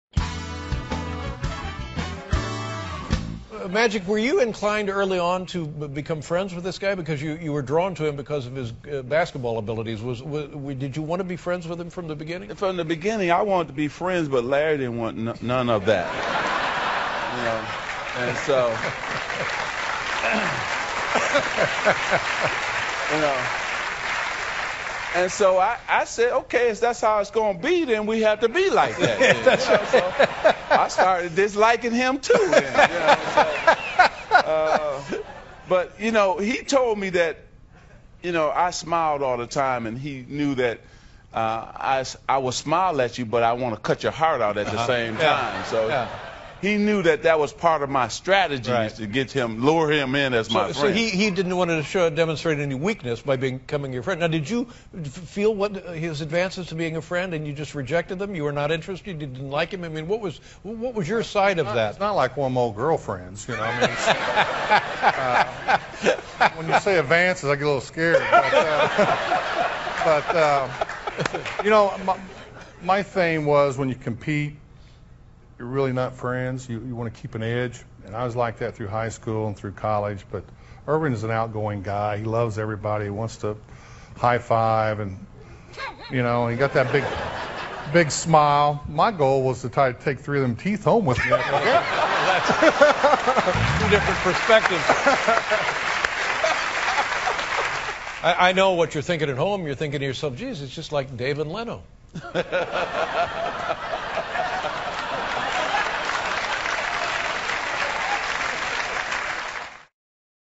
访谈录 2012-04-24&04-26 拉里.柏德和魔术师约翰逊专访 听力文件下载—在线英语听力室